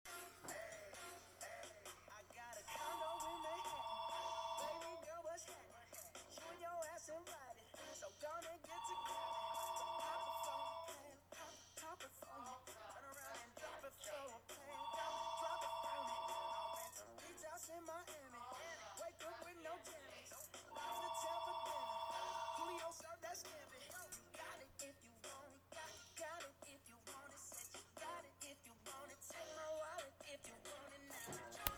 field recording